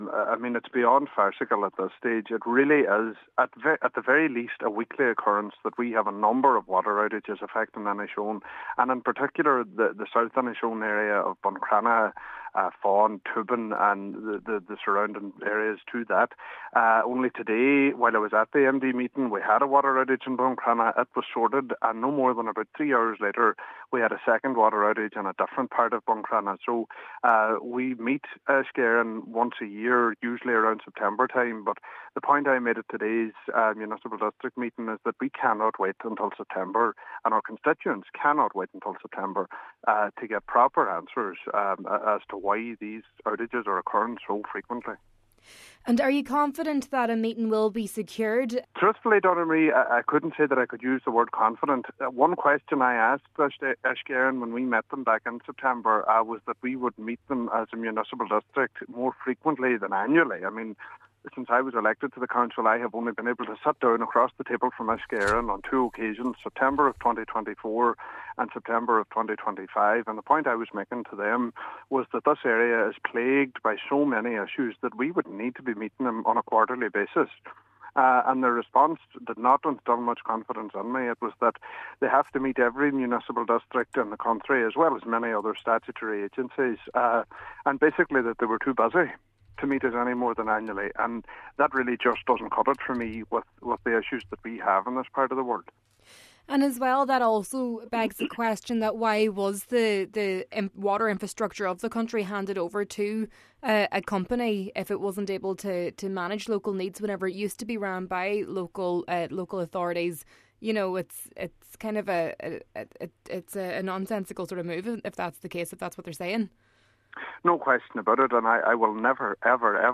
Cllr Bradley says he is not confident that a meeting will be arranged, adding that these concerns should have been addressed when the utility was being set up and removed from local authority control: